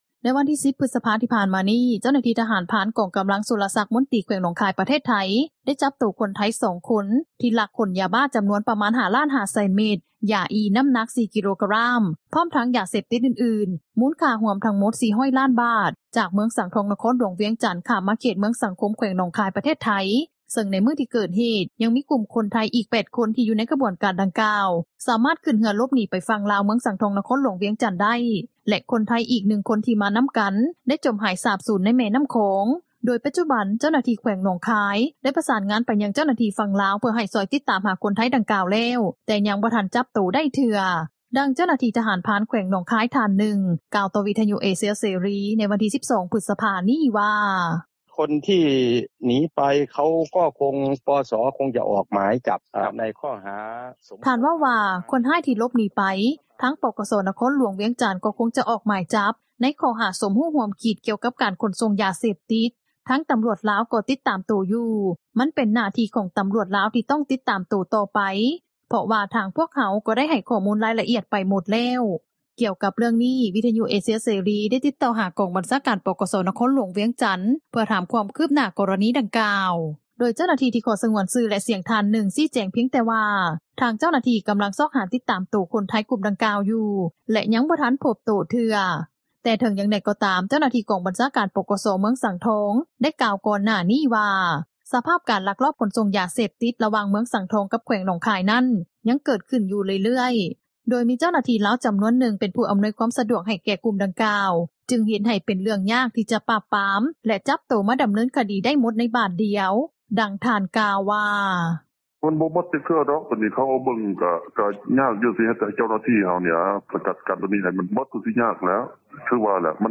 ດັ່ງເຈົ້າໜ້າທີ່ ທະຫານພຣານ ແຂວງໜອງຄາຍ ທ່ານນຶ່ງ ກ່າວຕໍ່ວິທຍຸເອເຊັຽເສຣີ ໃນວັນທີ່ 12 ພຶສພາ ນີ້ວ່າ:
ດັ່ງຍານາງກ່າວວ່າ: